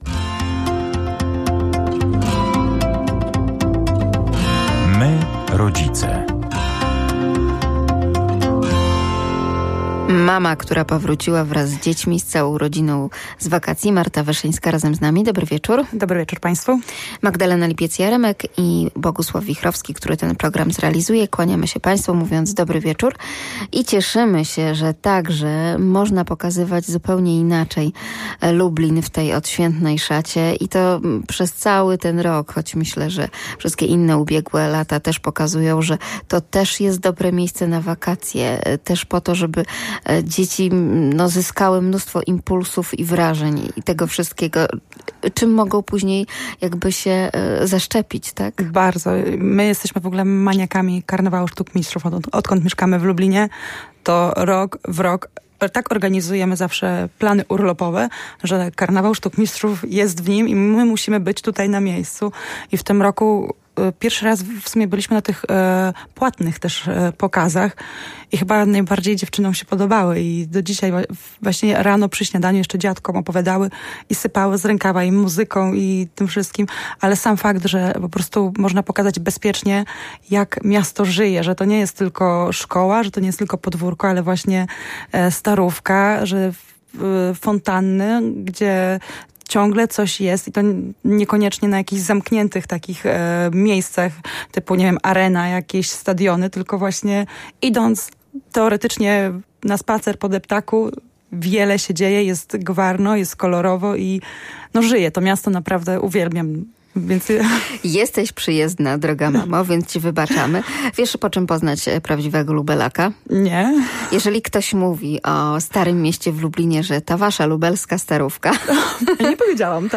O integracji sensorycznej porozmawiamy z blogującymi mamami